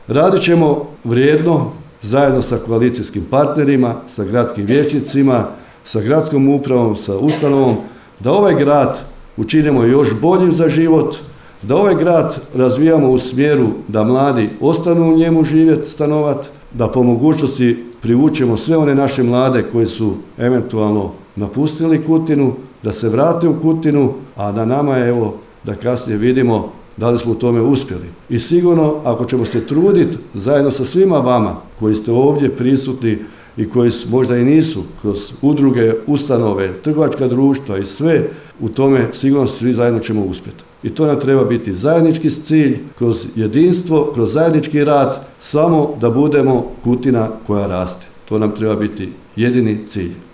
U povodu proslave Dana grada Kutine i obilježavanja Petrova u petak, 27. lipnja 2025. godine, održana je svečana sjednica Gradskog vijeća Grada Kutine.
Gradonačelnik Babić zaključuje kako jedini cilj Grada Kutine treba biti – grad koji raste